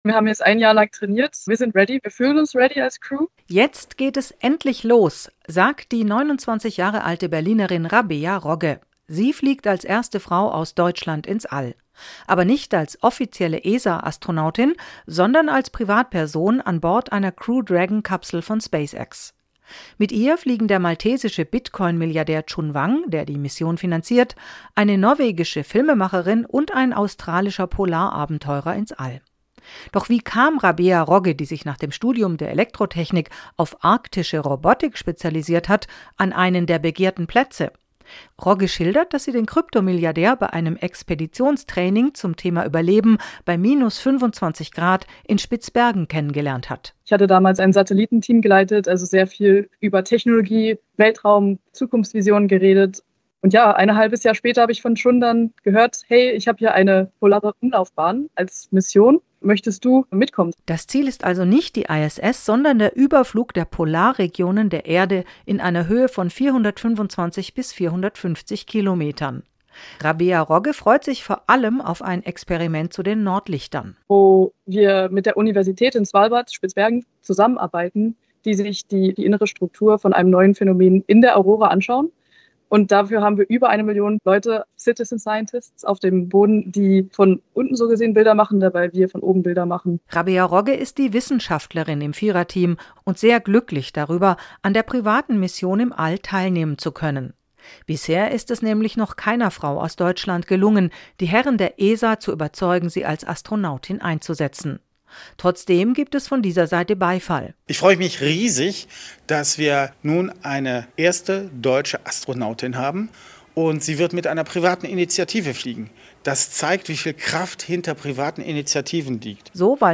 Die Kollegen von SWR Wissen Aktuell haben mit Rabea Rogge gesprochen – auch darüber, wie sie an einen der begehrten Plätze im Team der SpaceX-Mission „Fram2“ kam: